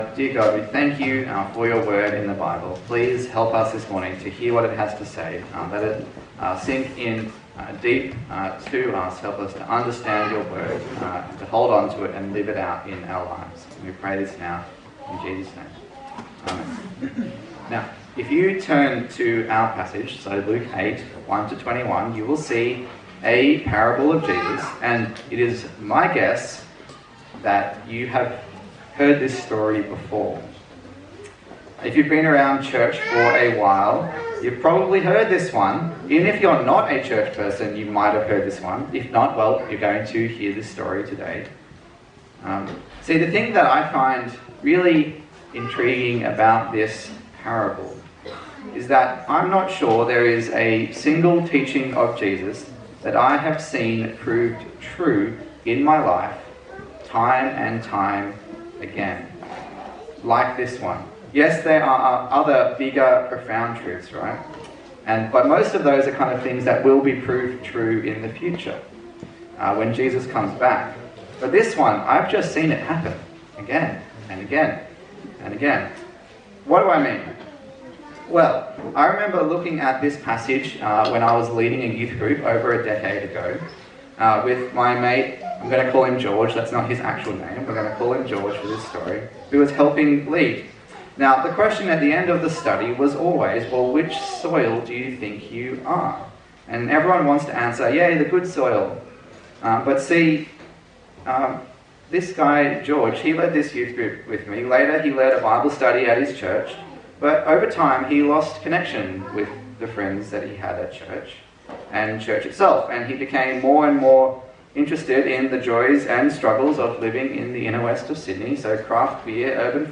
A sermon in the series on the Gospel of Luke
Service Type: Morning Service